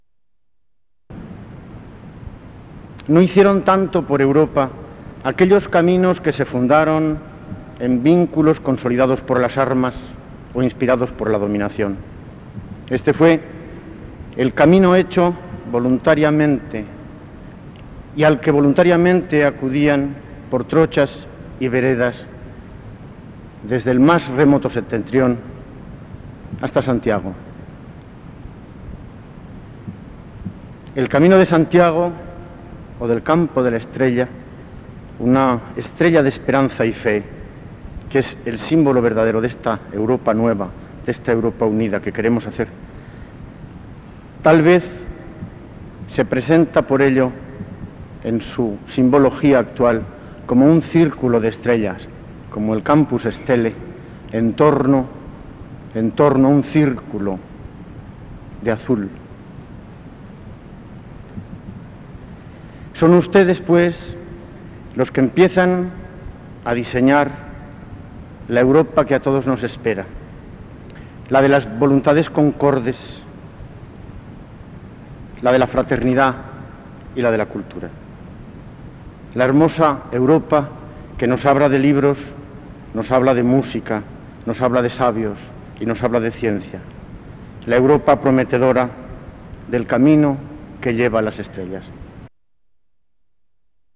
Bienvenida del Ilmo. Sr. D. Darío Vidal, consejero de Cultura y Educación del Gobierno de Aragón
I Congreso Internacional de Jaca. Saludo de las Autoridades. Miércoles 23 de septiembre, S.I. Catedral de Jaca, 1987